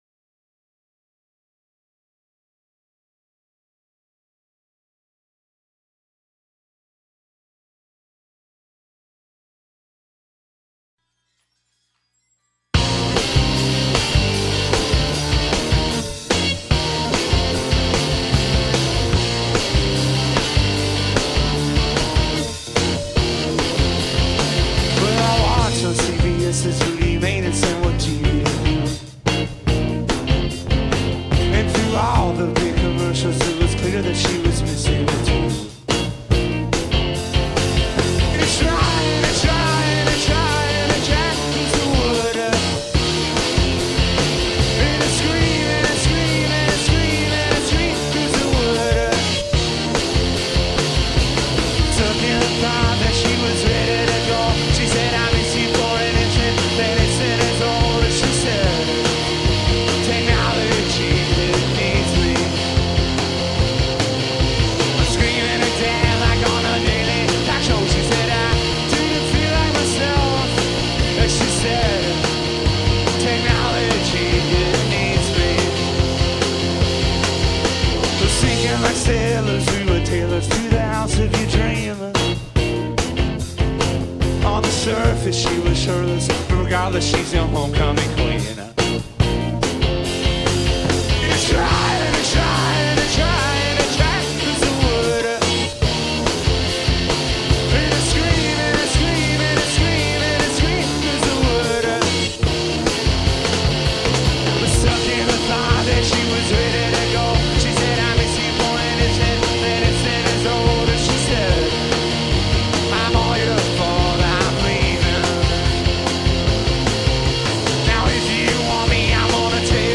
Le trio d’Athens composé des très jeunes
basse
2 sessions studio de l’ancien album